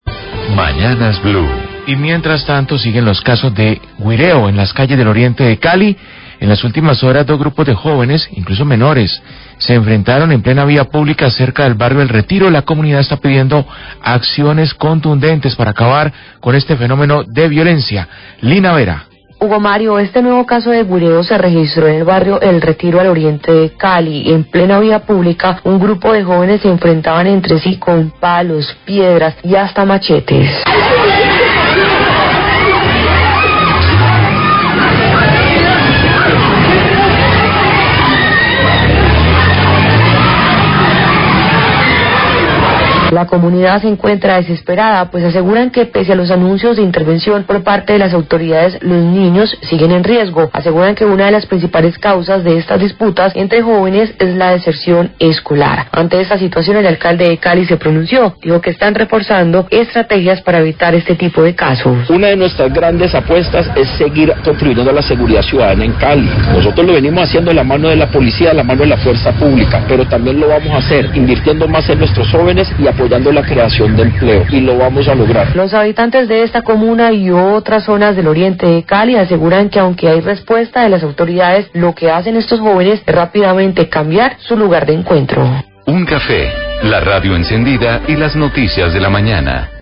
Alcalde de Cali habla de acciones para prevenir el 'guireo' en barrios del oriente
Radio